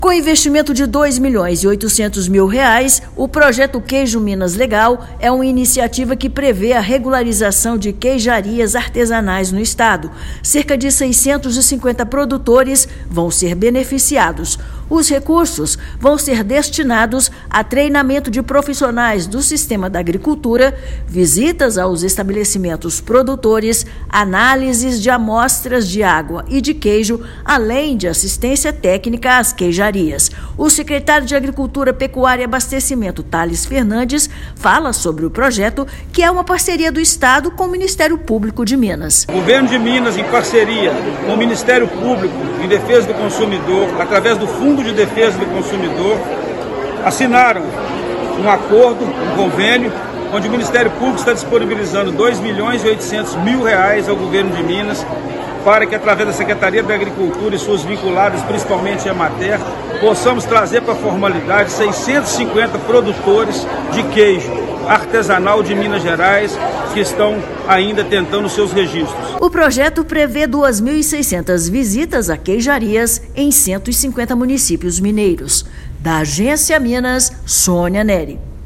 O projeto é fruto de parceria com do Governo de Minas com o Ministério Público de Minas Gerais (MPMG). Ouça a matéria de rádio.